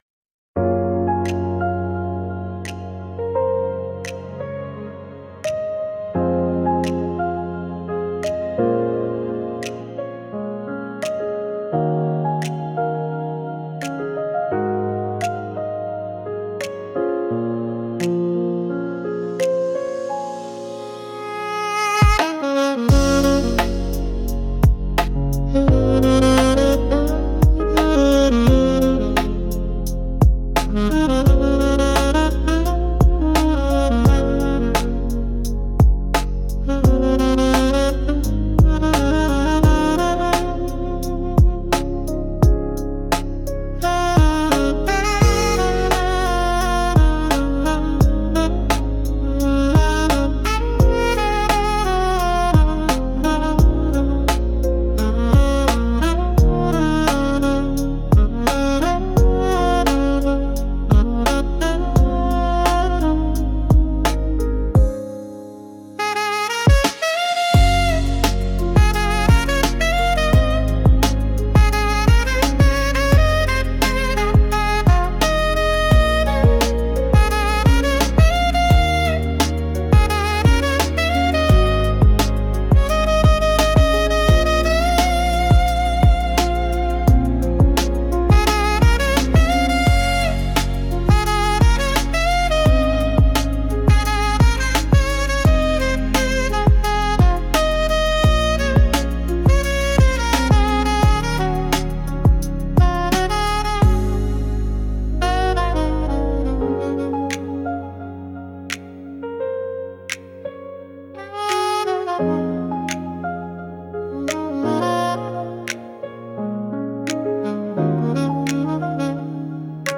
大人な雰囲気